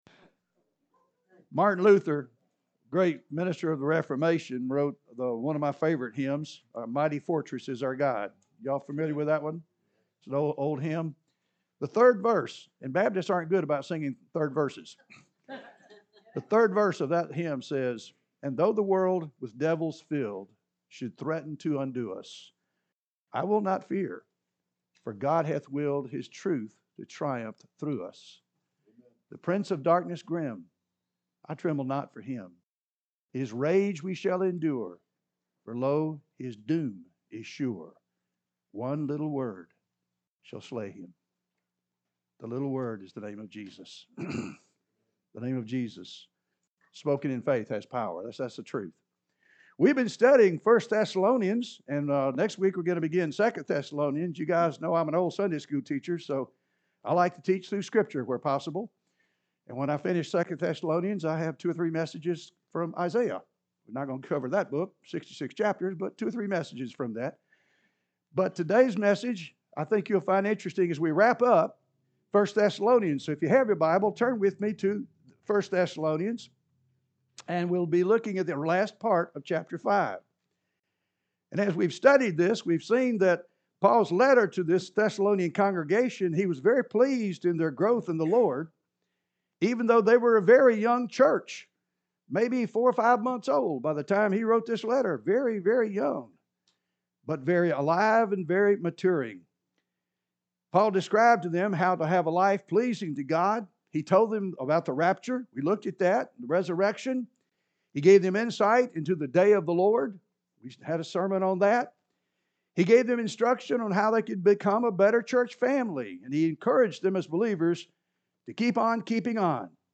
(Sermon Series)